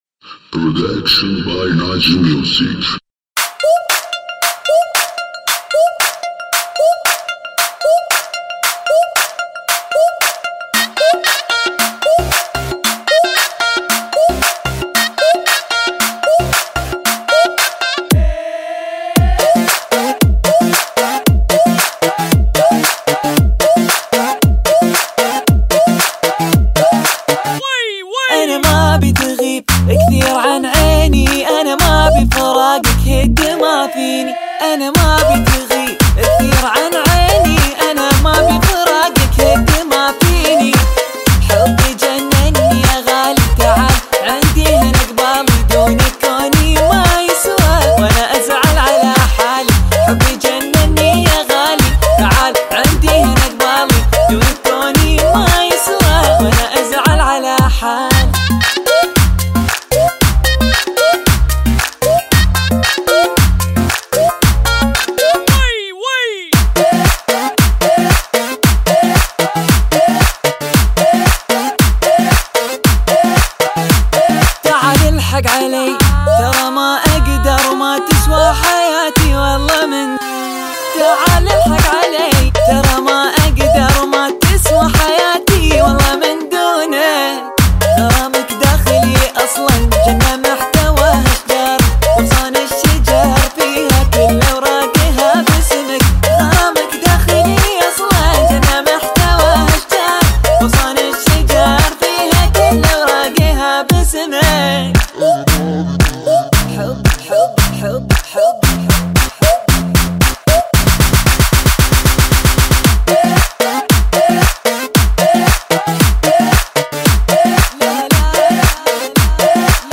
ریمیکس
دانلود ریمیکس شاد عربی
برای رقص